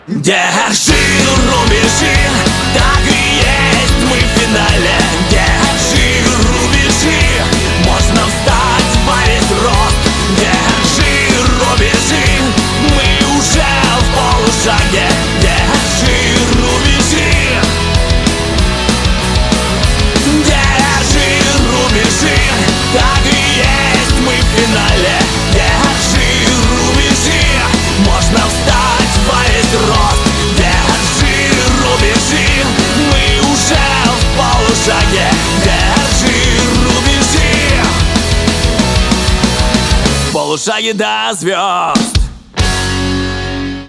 • Качество: 192, Stereo
наш русский классический рок